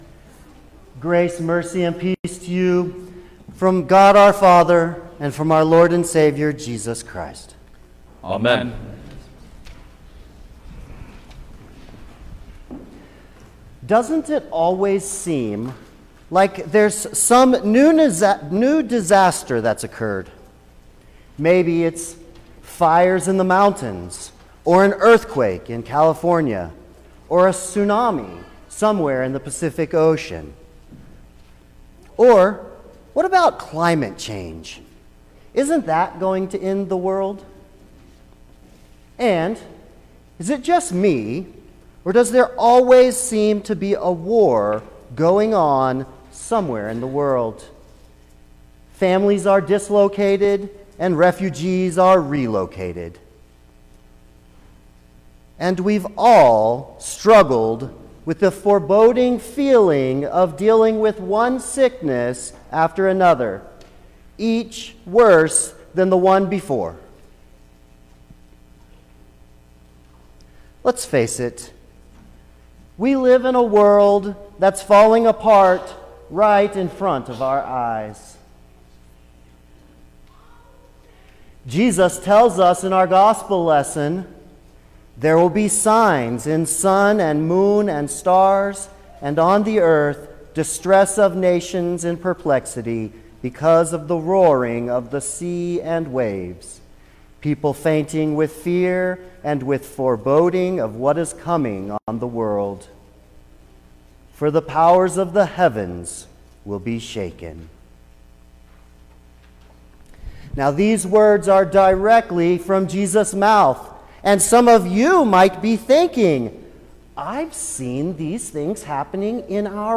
December-5_2021_Second-Sunday-in-Advent_Sermon-Stereo.mp3